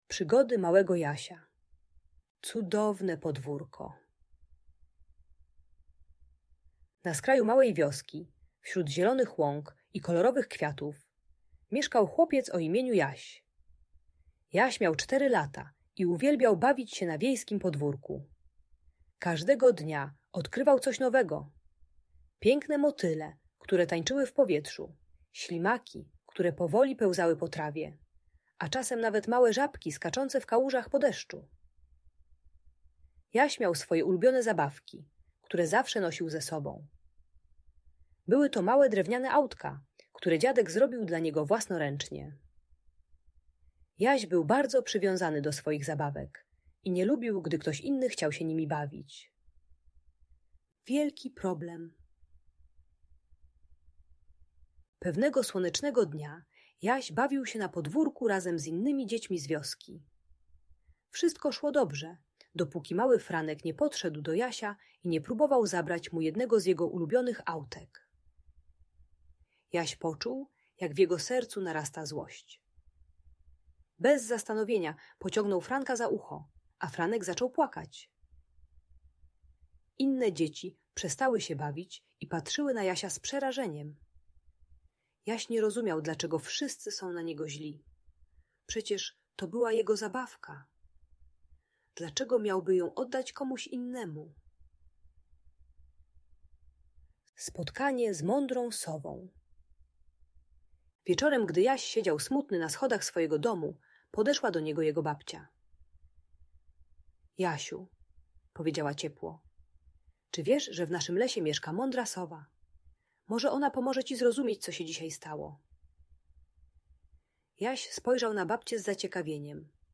Przygody Małego Jasia - Audiobajka